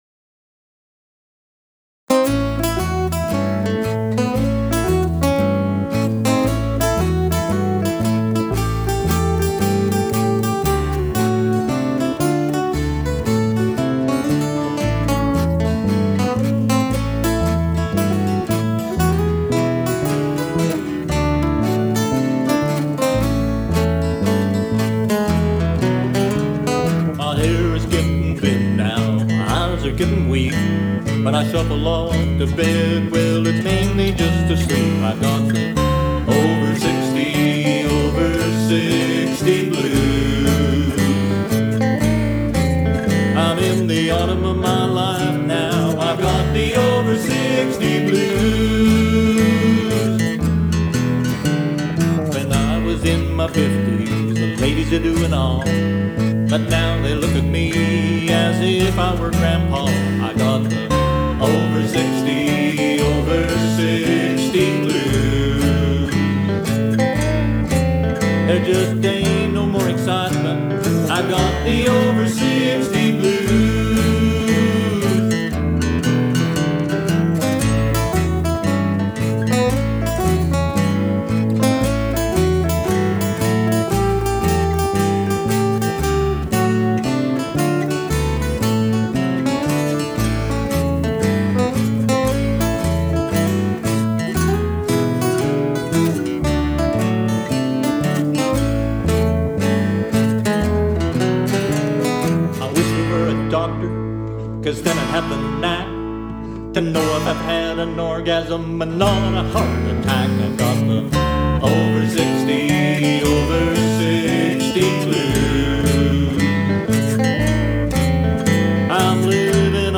Recorded at River Road Recording Studio, Ottawa, ON